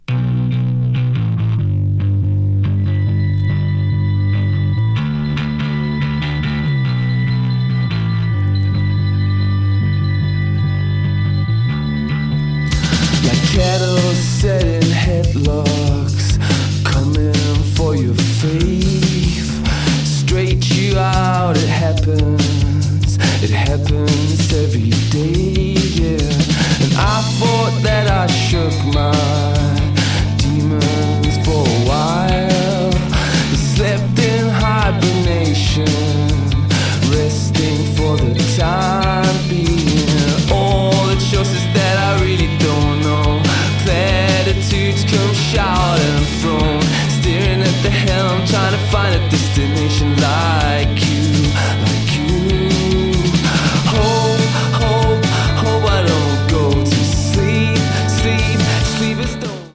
New Release Indie Rock New Wave / Rock